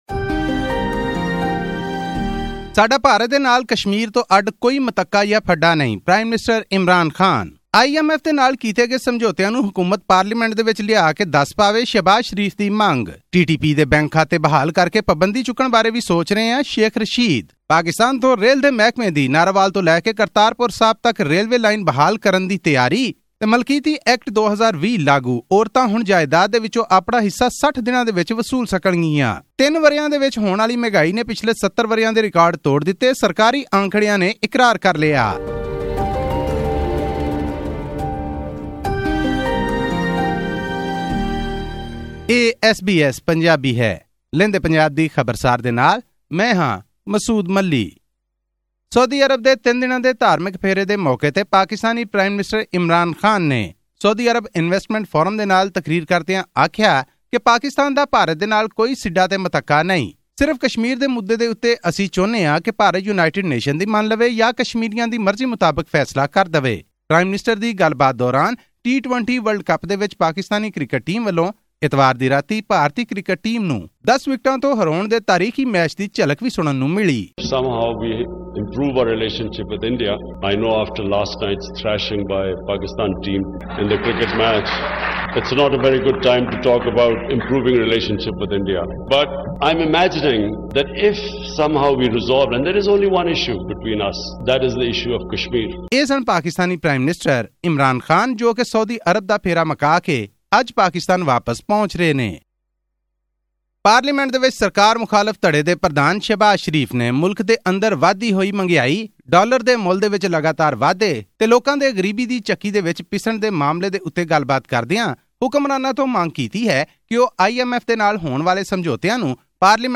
Prime Minister Imran Khan emphasised the need for boosting ties with India but said that after his country's recent win in the T20 World Cup, it was "not a good time" to initiate this conversation. This and more in our weekly news update from Pakistan.